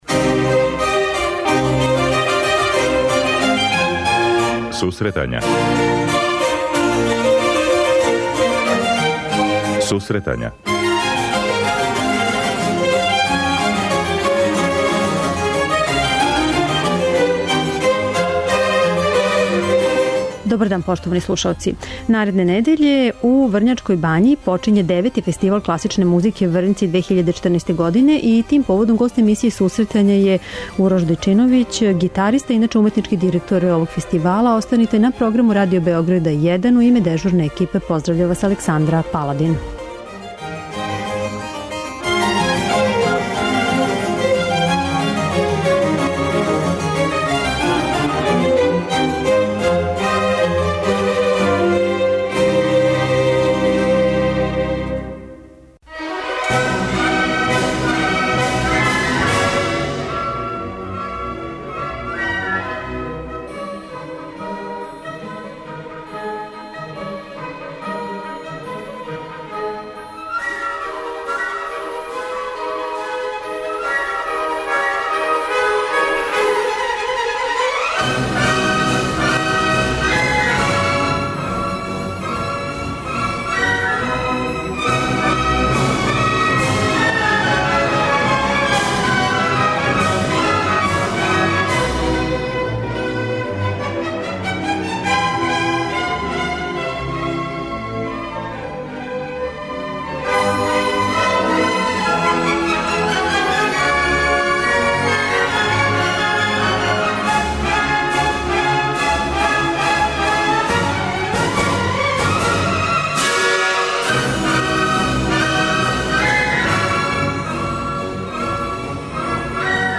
преузми : 25.72 MB Сусретања Autor: Музичка редакција Емисија за оне који воле уметничку музику.